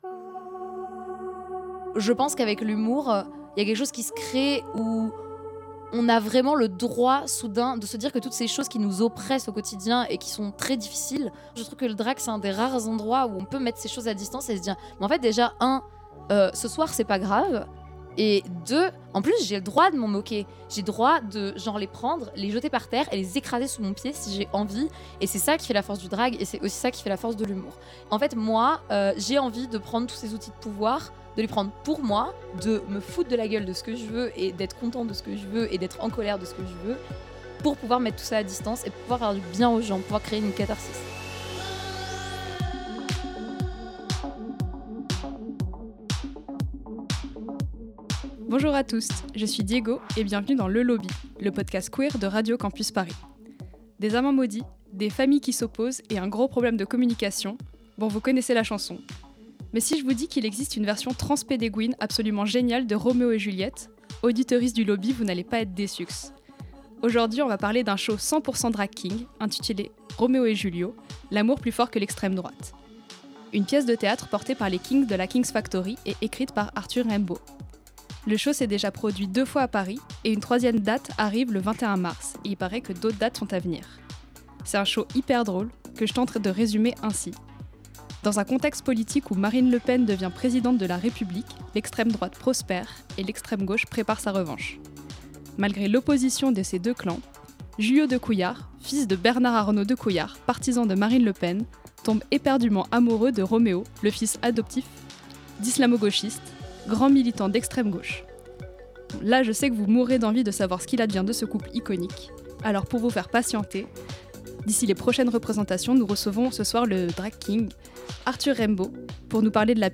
Magazine Société